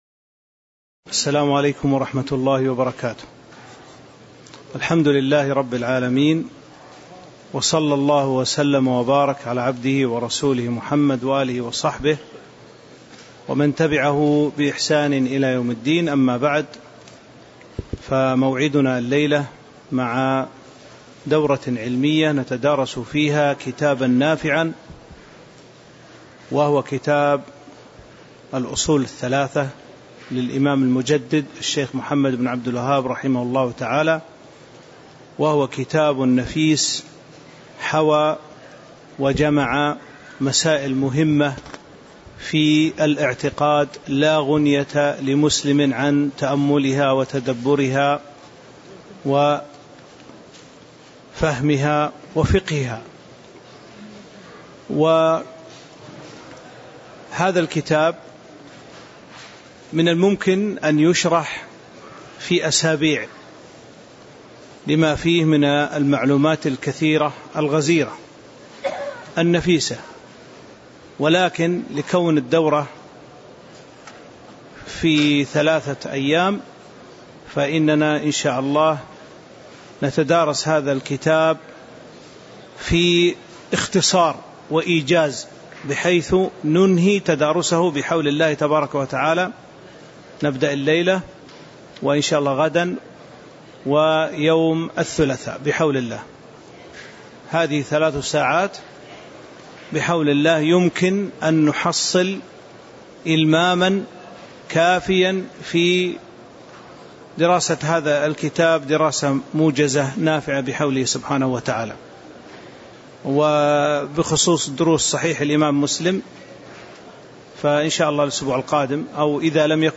تاريخ النشر ١٥ ذو القعدة ١٤٤٤ هـ المكان: المسجد النبوي الشيخ